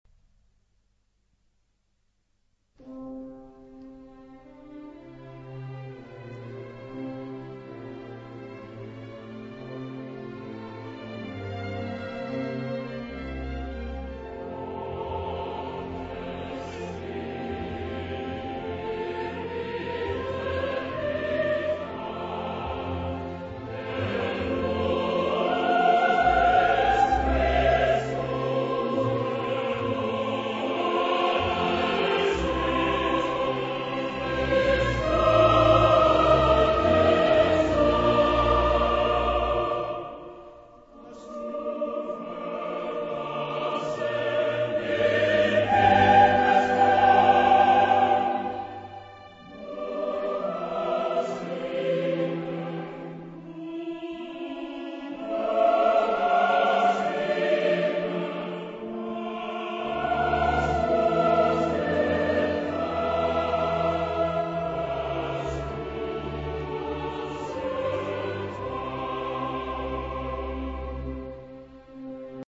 Genre-Style-Form: Sacred ; Cantata ; Romantic
Mood of the piece: mystical
Type of Choir: SSAATTBB  (8 mixed voices )
Instrumentation: Orchestra  (24 instrumental part(s))
Tonality: A major